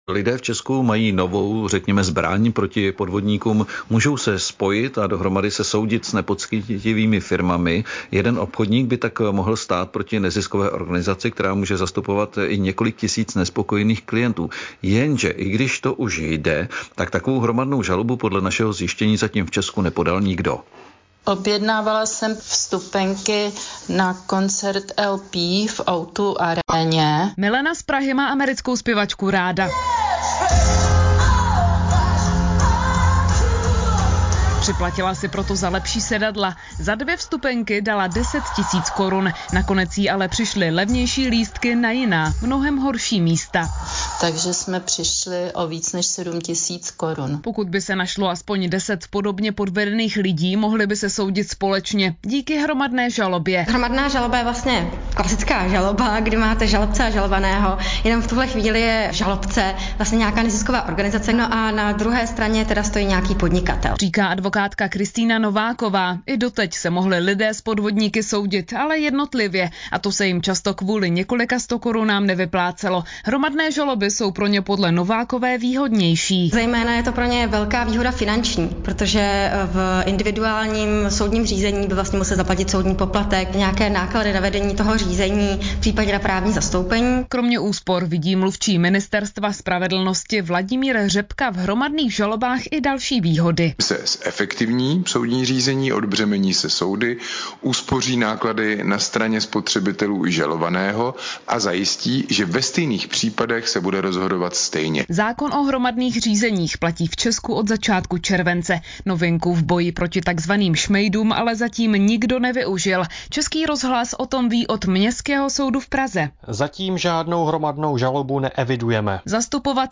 Rozhovor k hromadnému soudnímu řízení